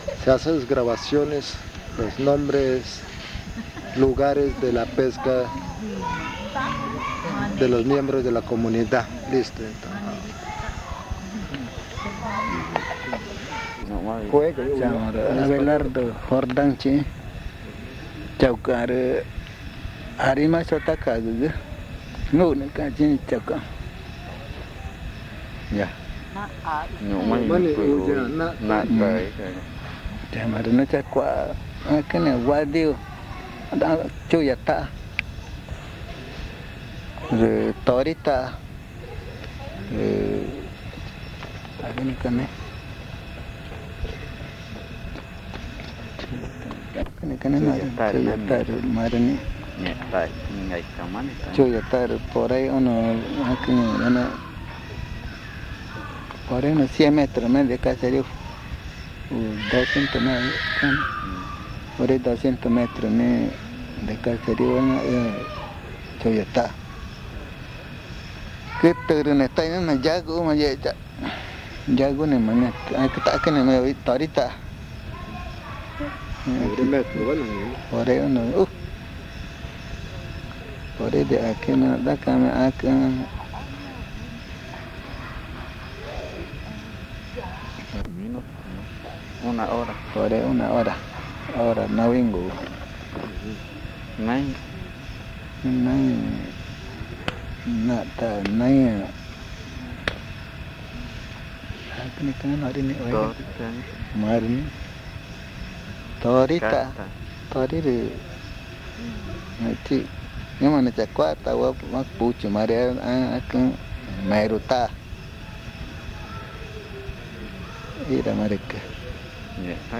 San Antonio de los Lagos, Amazonas (Colombia)